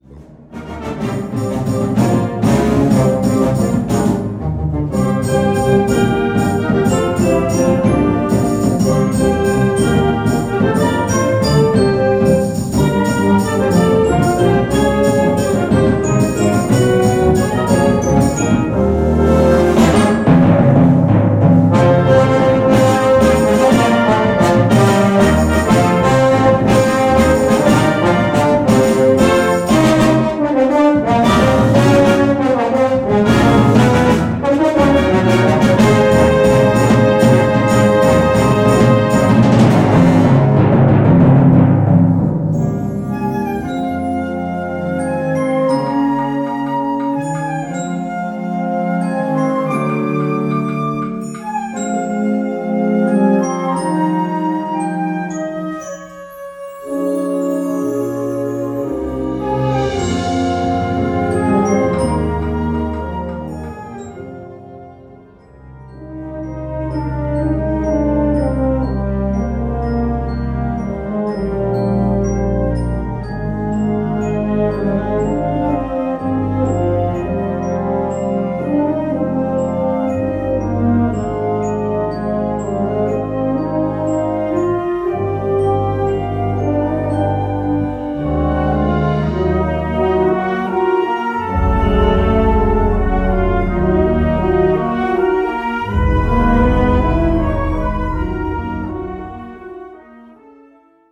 Categoría Banda sinfónica/brass band
Subcategoría Música de concierto / Música sinfónica
Instrumentación/orquestación Ha (banda de música)